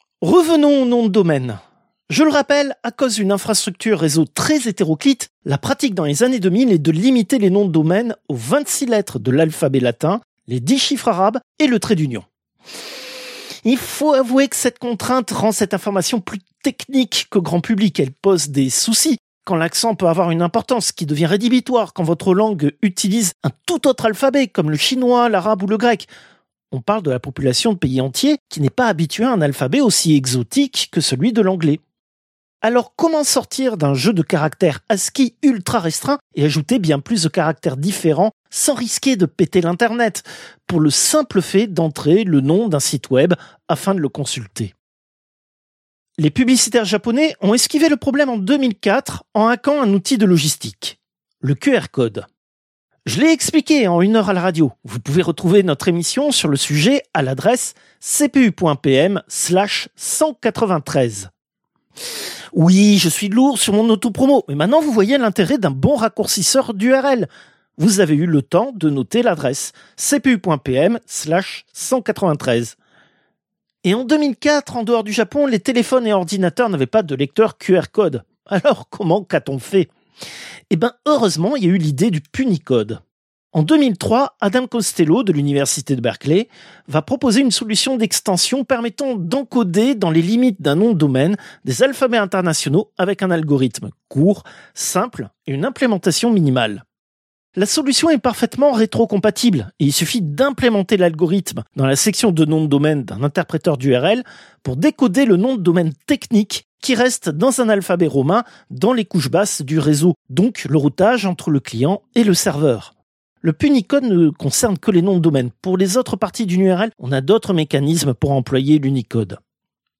Extrait de l'émission CPU release Ex0226 : L'adresse était trop longue.